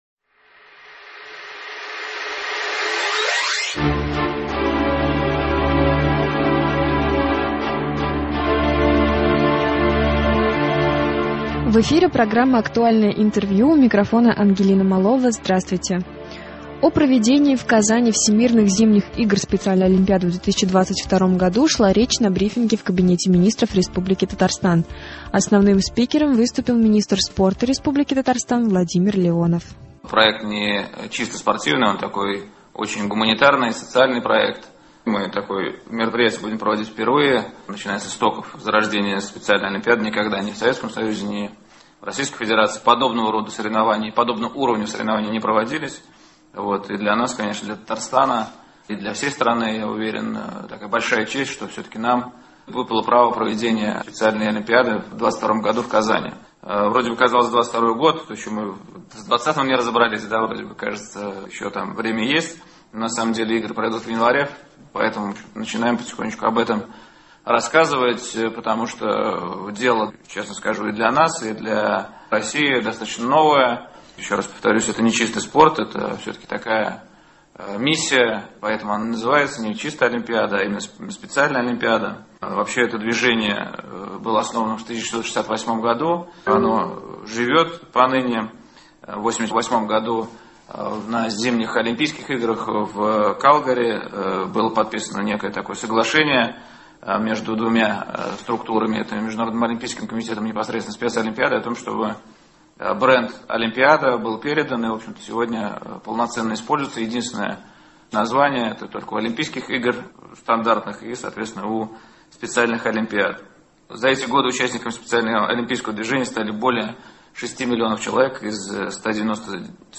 В столице Татарстана началась масштабная подготовка к проведению Всемирных зимних игр Специальной Олимпиады 2022 года. О ходе подготовки и программе игр рассказал на брифинге в Доме Правительства РТ министр спорта Республики Татарстан Владимир Леонов.